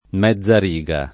vai all'elenco alfabetico delle voci ingrandisci il carattere 100% rimpicciolisci il carattere stampa invia tramite posta elettronica codividi su Facebook mezzariga [ m Hzz ar &g a ] s. f. (tecn.); pl. mezzerighe — anche mezza riga [id.]